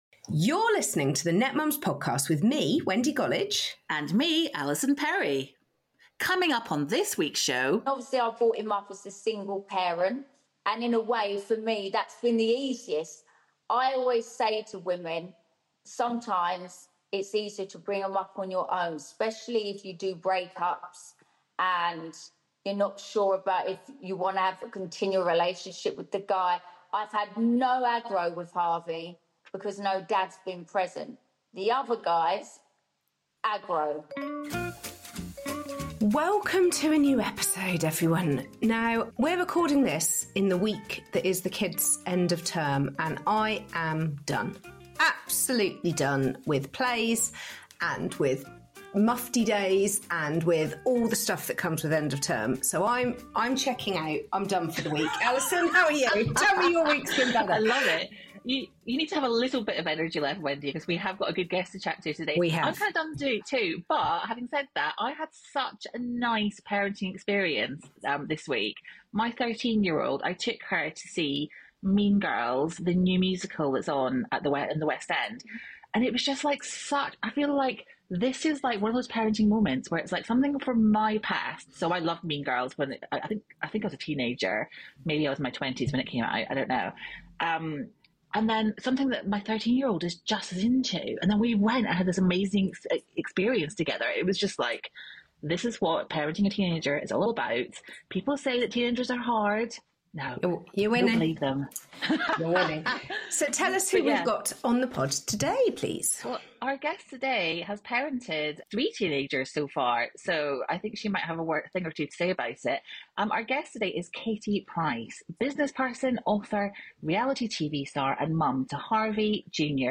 This week, we have an incredible guest who needs no introduction – the one and only Katie Price.